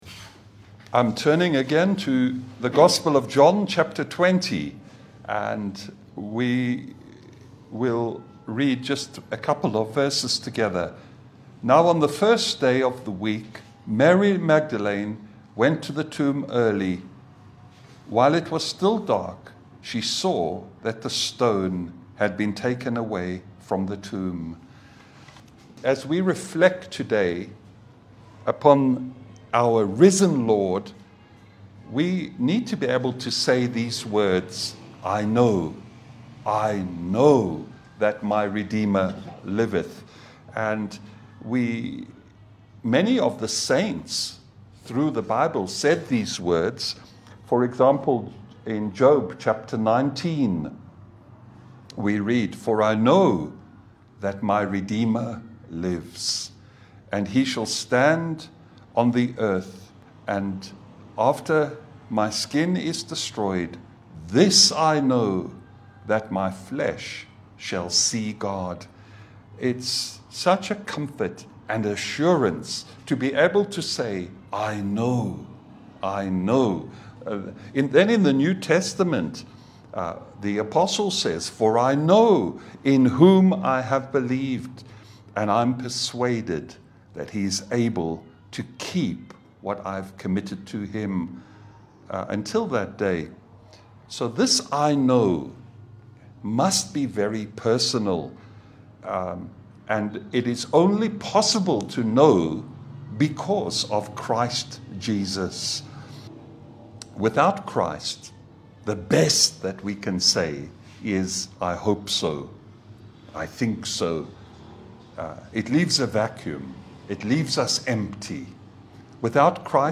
Passage: John 20:1 Service Type: Sunday Bible fellowship